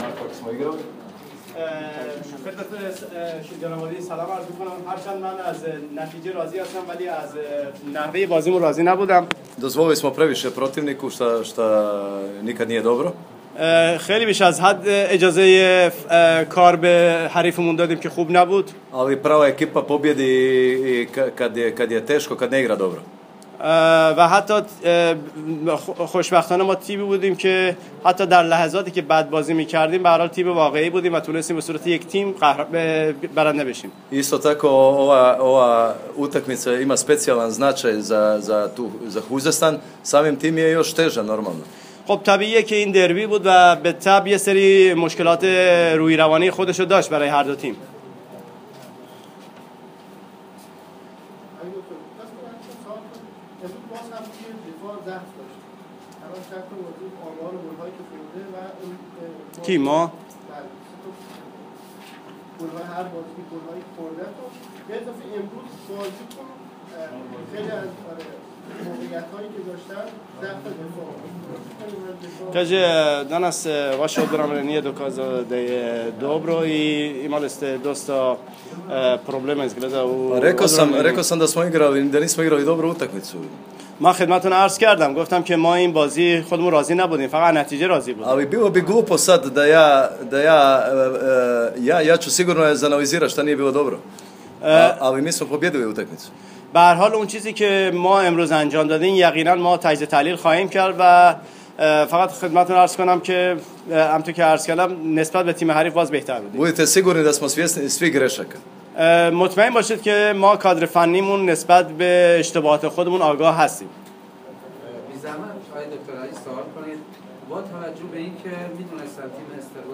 کنفرانس خبری فولاد - استقلال خوزستان؛ اسکوچیچ: وکیا فوق العاده بازی کرد؛ زمین تختی آماده نبود (بهمراه فایل صوتی مصاحبه)